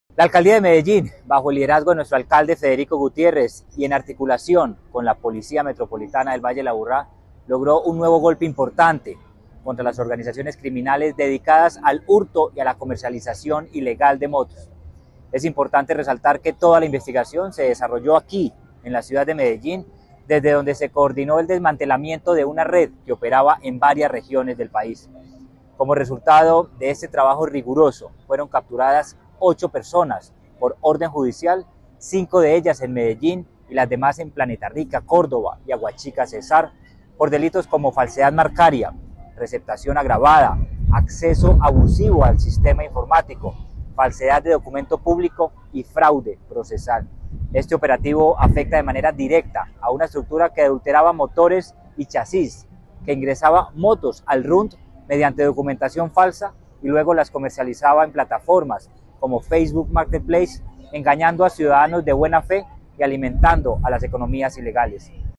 Declaraciones-secretario-de-Seguridad-y-Convivencia-Manuel-Villa-Mejia-1.mp3